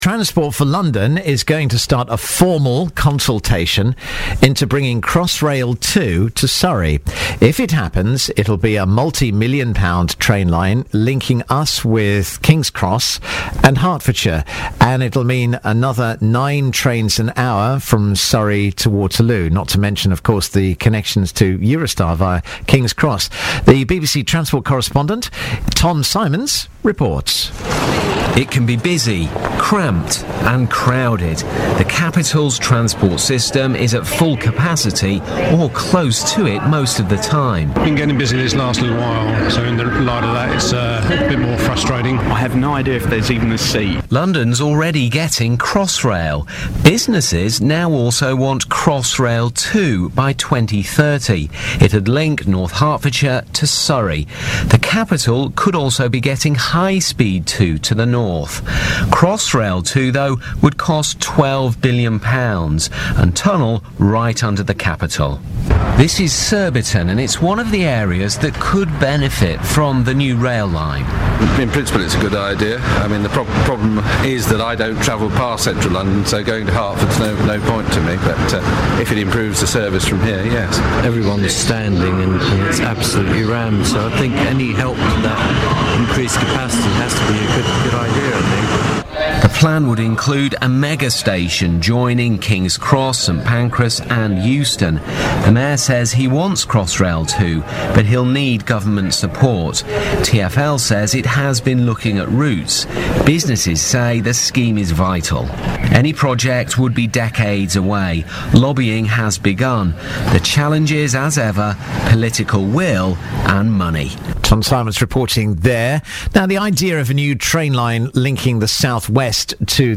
BBC Surrey interview about Crossrail Two
Audio courtesy of BBC Surrey.